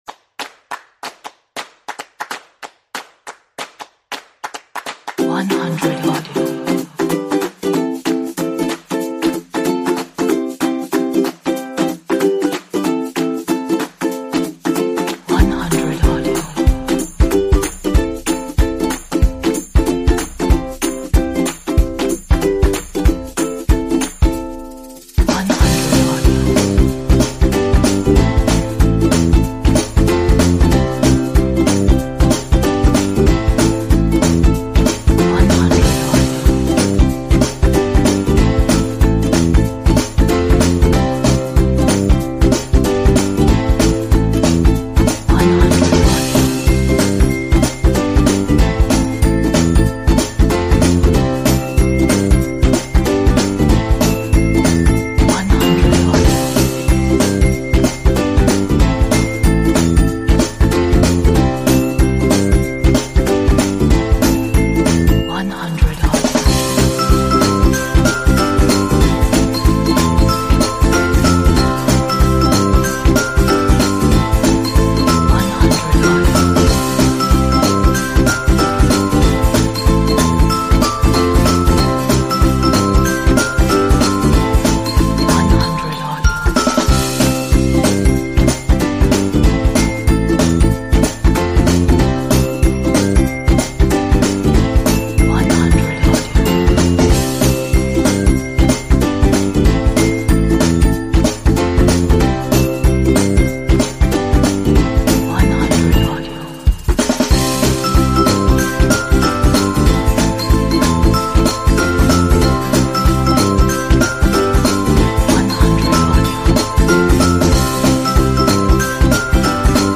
It also has a sense of playfulness and joyful innocence.
BPM 94